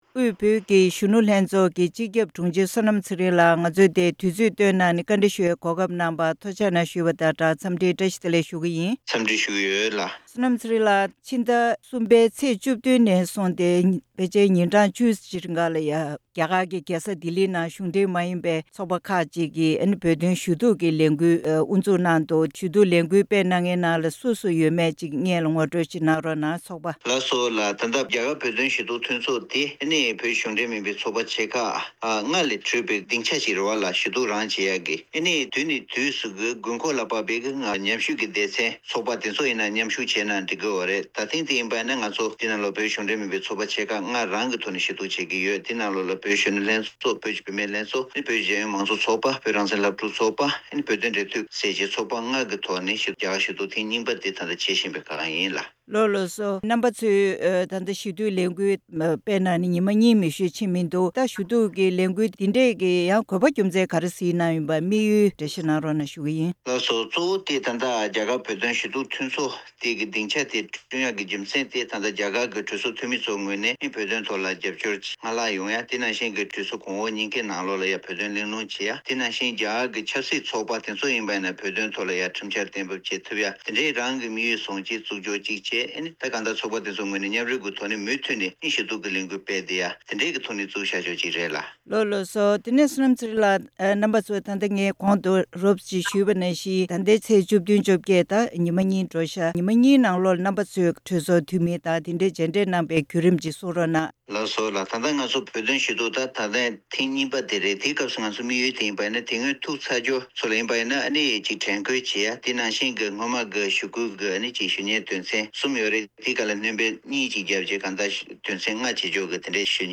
གནས་འདྲི་ཞུས་པ་ཞིག་གསན་གནང་གི་རེད།